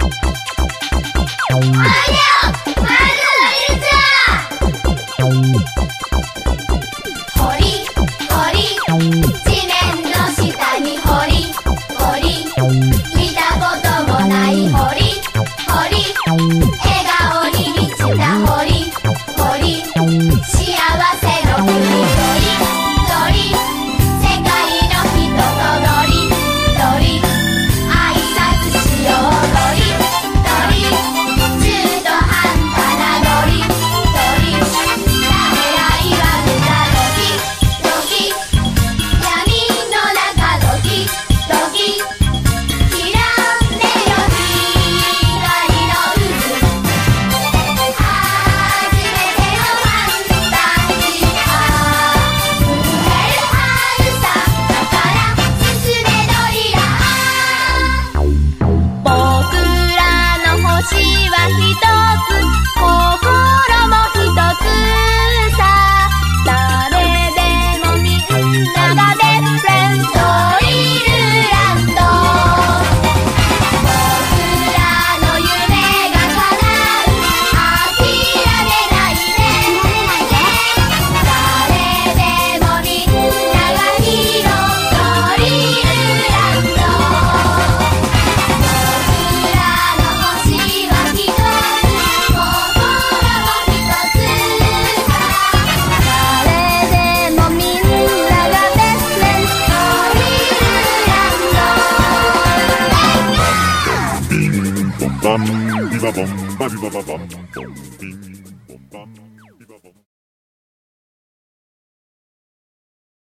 BPM130
Audio QualityPerfect (High Quality)
The ending theme